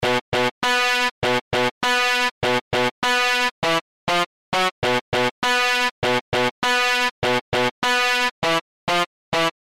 ギター素材 　ビート素材
44guitar.mp3